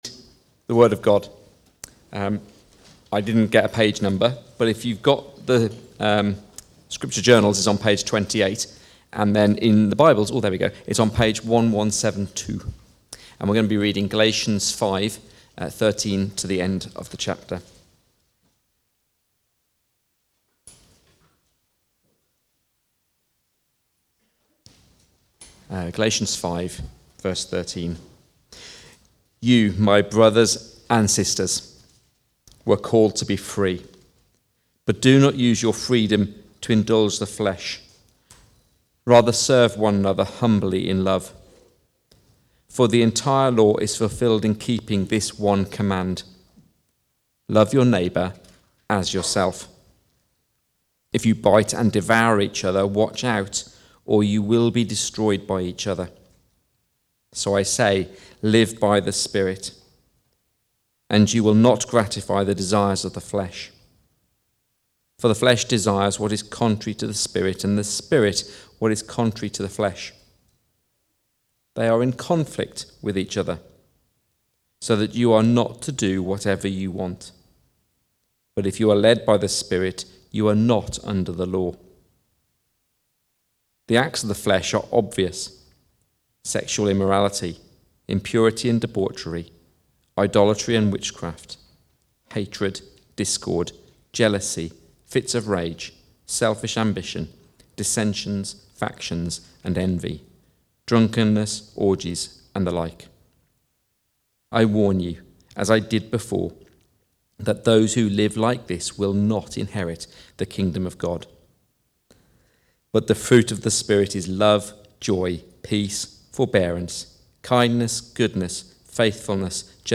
The Gospel Lived Out (Galatians 5:13-26) from the series Galatians - the Glorious Gospel. Recorded at Woodstock Road Baptist Church on 03 November 2024.